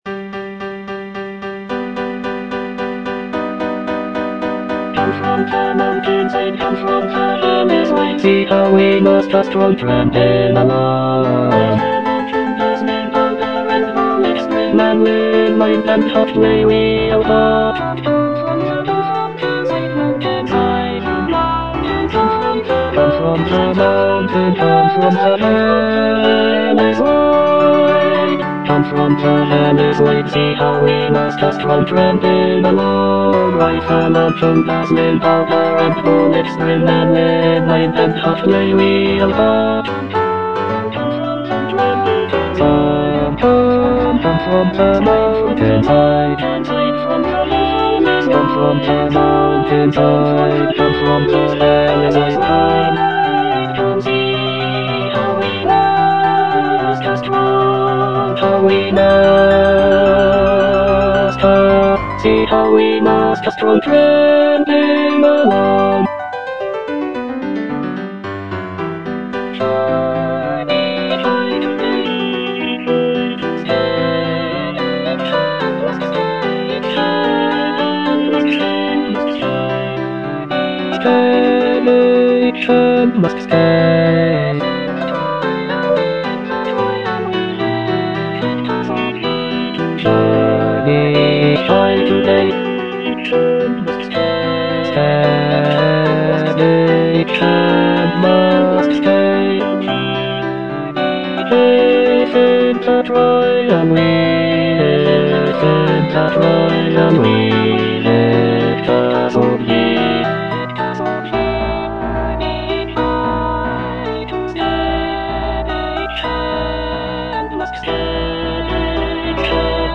(bass I) (Emphasised voice and other voices) Ads stop